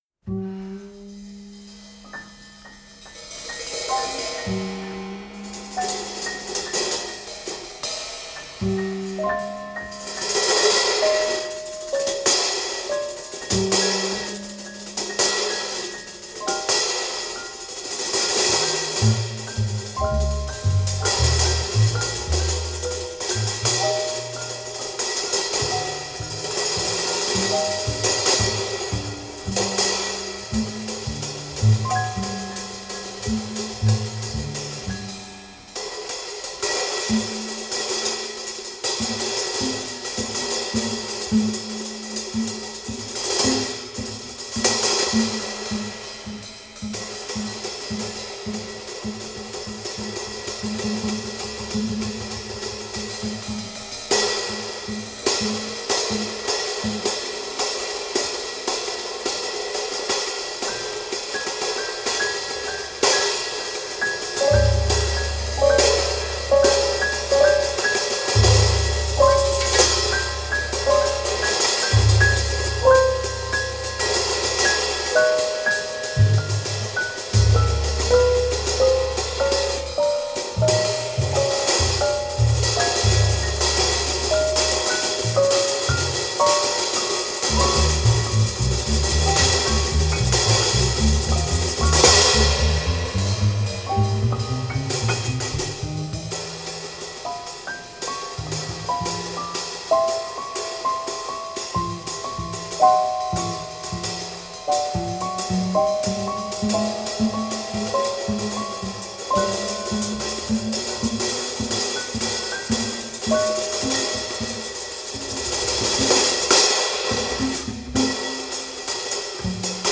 • Жанр: Джаз